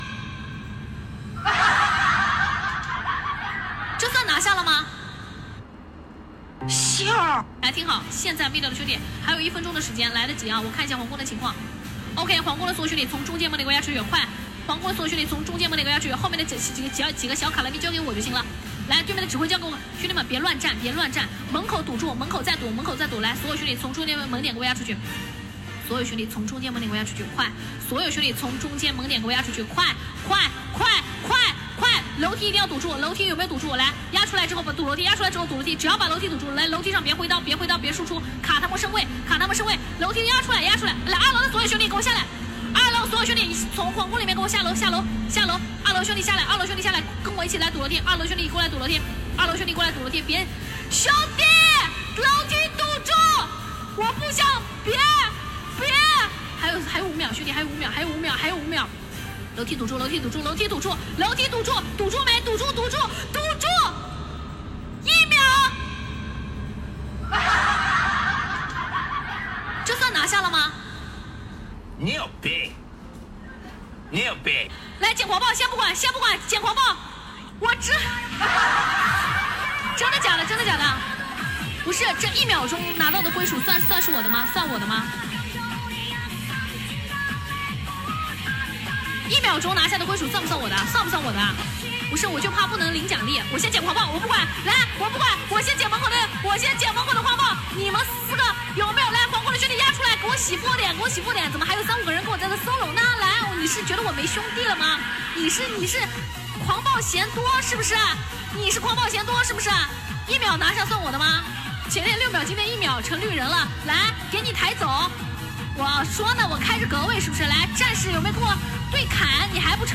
女主播高声指挥，攻城战况紧张，一声令下，瞬间一波清空皇宫守军，夺下沙巴克城，堪称绝世神操作！